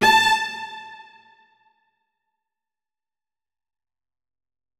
328d67128d Divergent / mods / Hideout Furniture / gamedata / sounds / interface / keyboard / strings / notes-57.ogg 53 KiB (Stored with Git LFS) Raw History